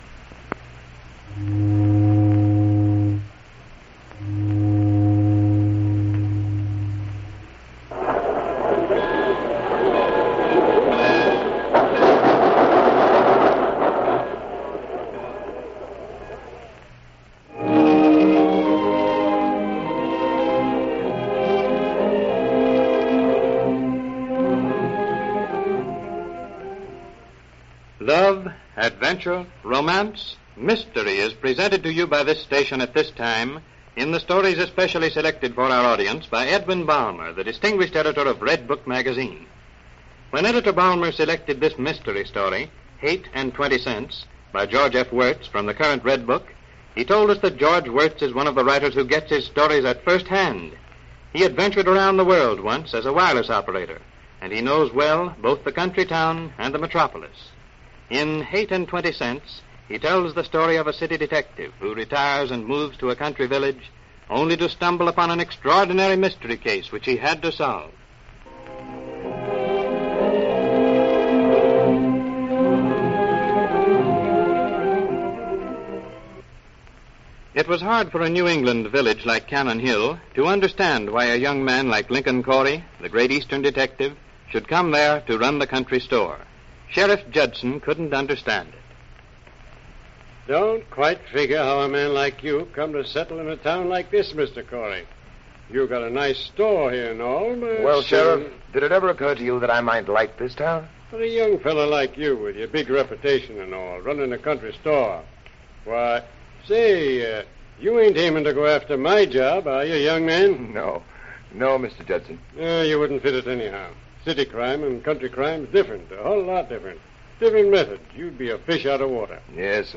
"Redbook Dramas" was a cherished radio series in the early 1930s that brought narratives from Redbook Magazine to life for listeners eager for captivating stories.